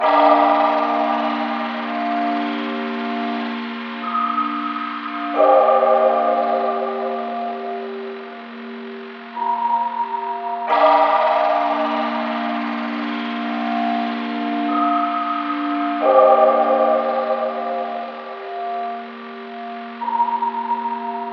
太空小说音效 " 07066外星搜索无人机
描述：外星人无人机的声音效果
标签： 声景 嗡嗡声 背景 无人驾驶飞机 外星人 环境 嗡嗡声 扫描 科幻 噪声 搜索
声道立体声